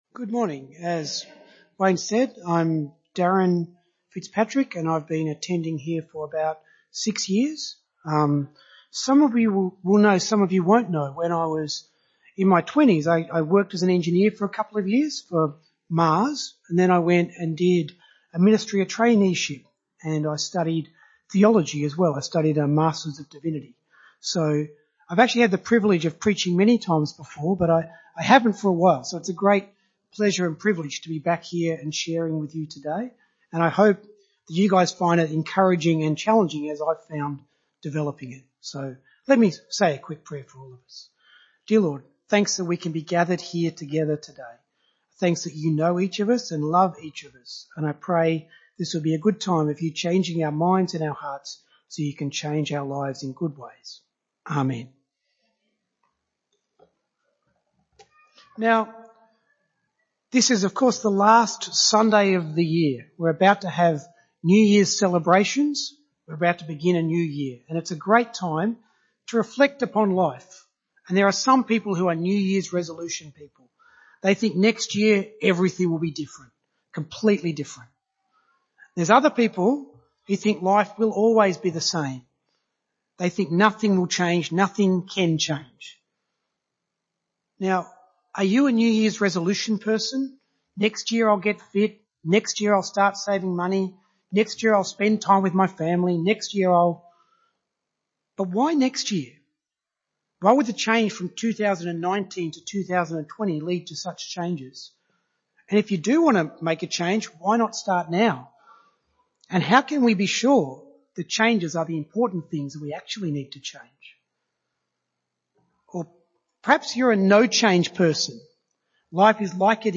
Series: A Sunday Sermon From BAC
Service Type: Sunday Morning